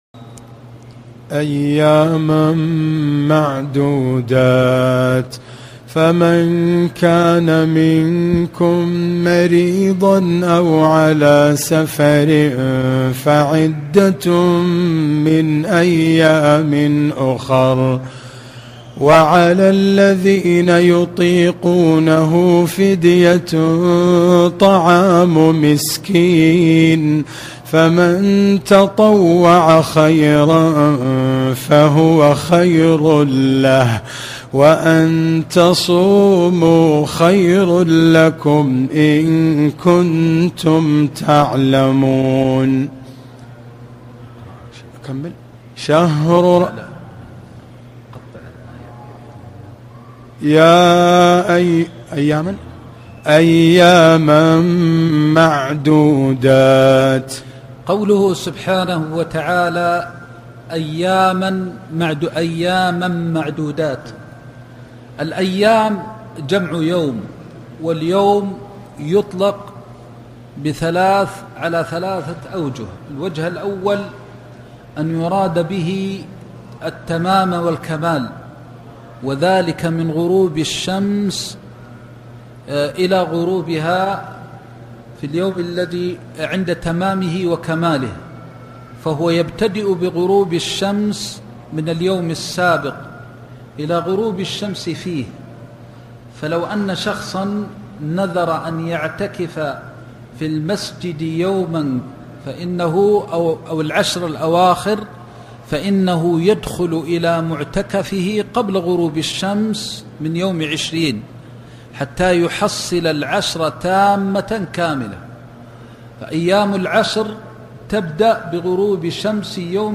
دروس تفسير آيات الصيام(الحرم المكي)درس (1)-قوله سبحانه(أياماً معدودات)